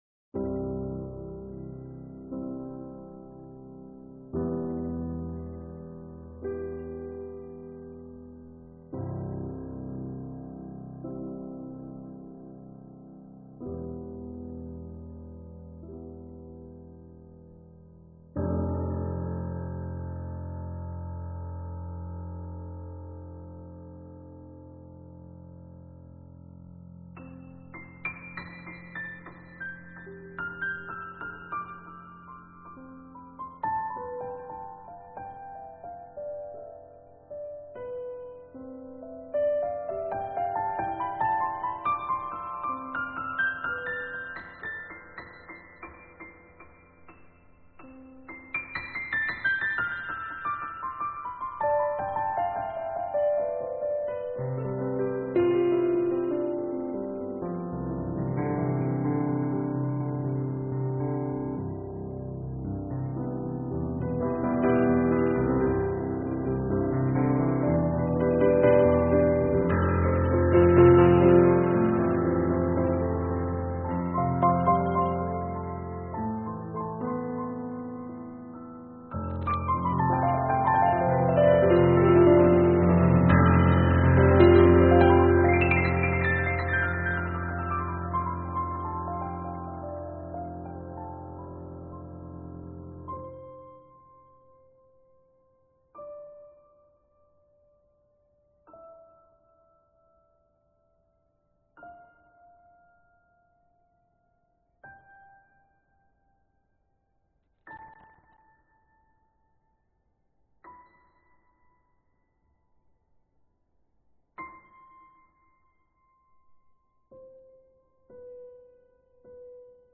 Pianossa